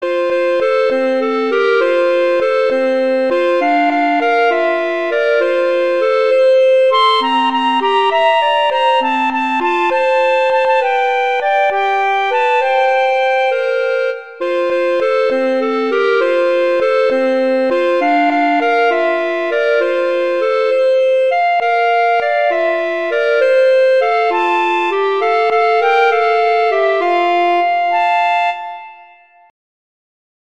Instrumentation: two clarinets
arrangements for two clarinets